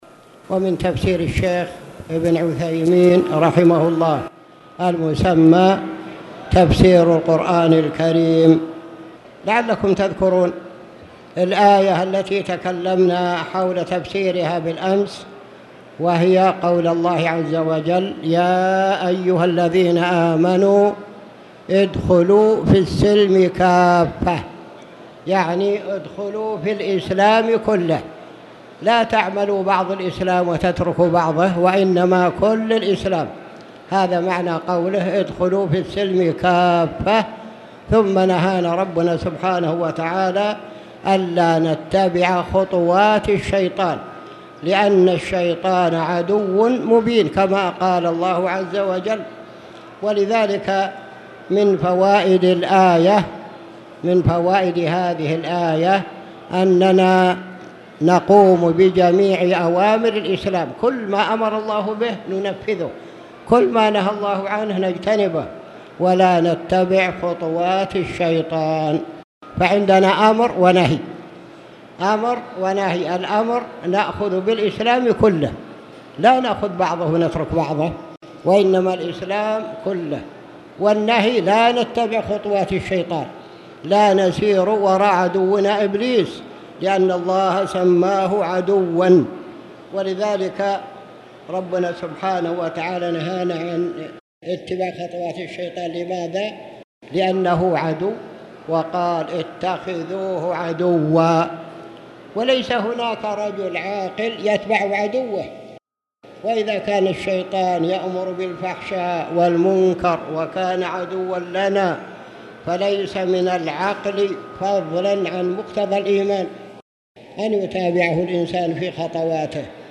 تاريخ النشر ١ رمضان ١٤٣٧ هـ المكان: المسجد الحرام الشيخ